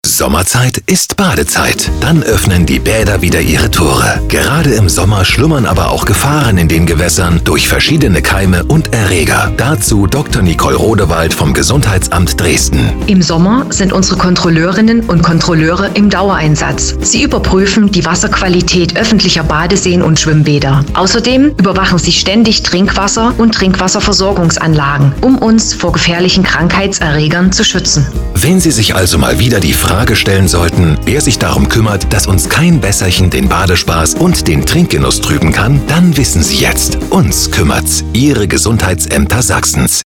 OEGD_Radiospot_Wasserqualitaet.mp3